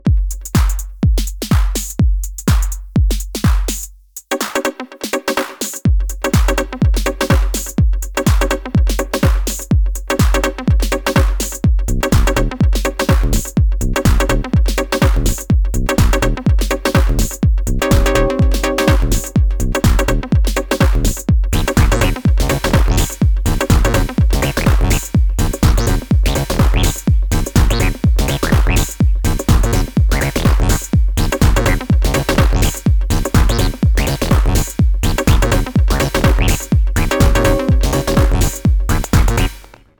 starting with a blank pattern and the CoreVault presets, three minutes later, instant fun!